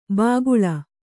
♪ bāguḷa